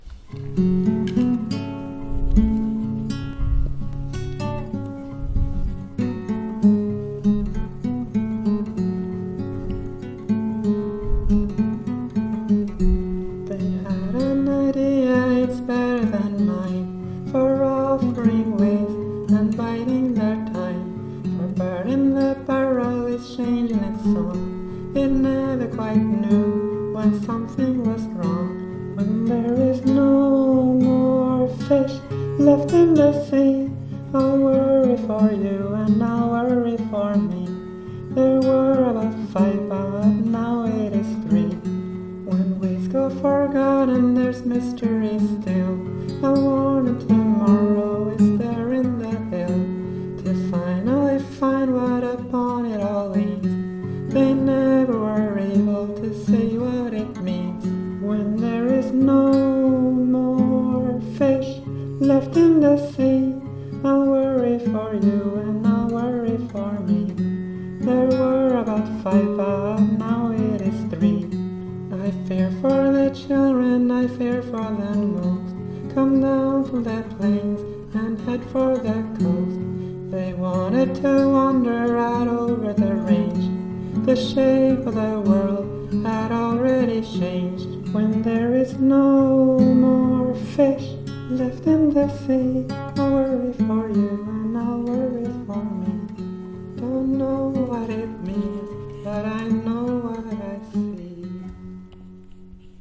Re: No More Fish Speaking of sad, sad songs…